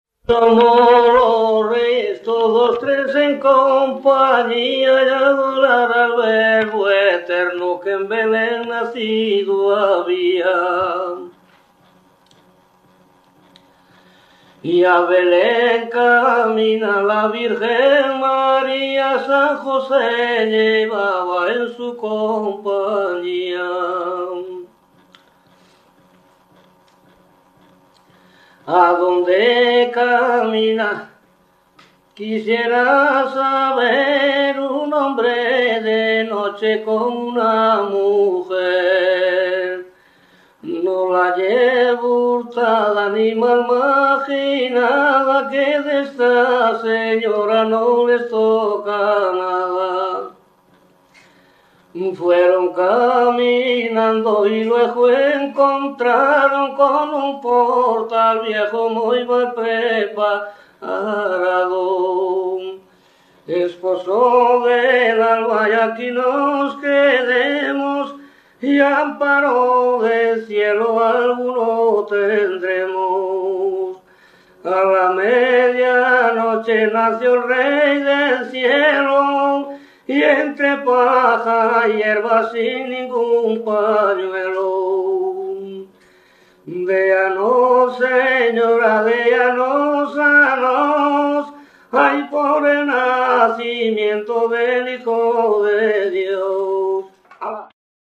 Tipo de rexistro: Musical
LITERATURA E DITOS POPULARES > Cantos narrativos
Lugar de compilación: Frades - Céltigos - Campoduro
Soporte orixinal: Casete
Instrumentación: Voz
Instrumentos: Voz masculina